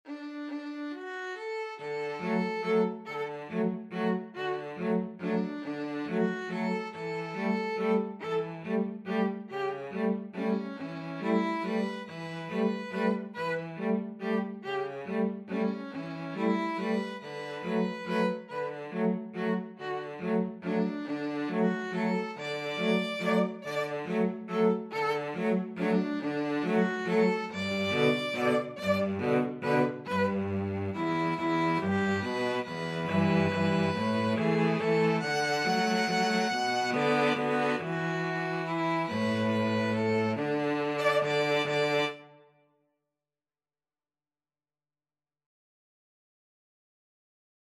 3/4 (View more 3/4 Music)
=140 Slow one in a bar